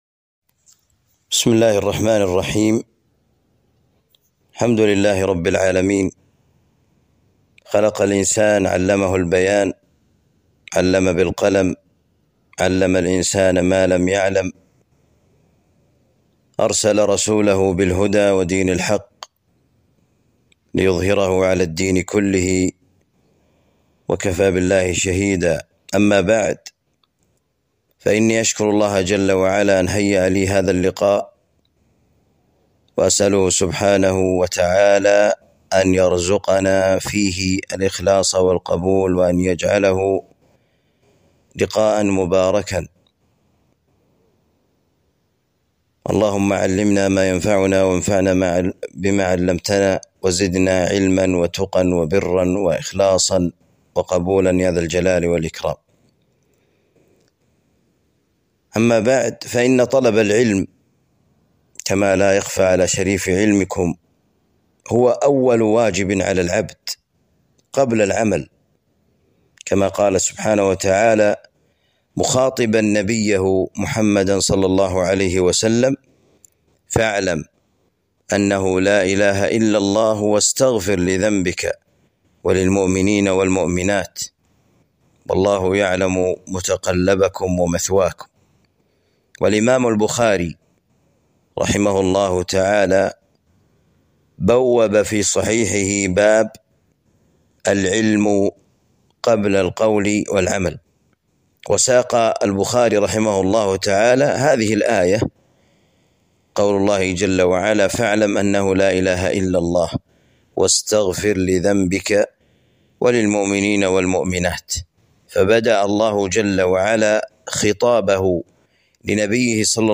محاضرة بعنوان طالب العلم وعلاقته بوسائل التواصل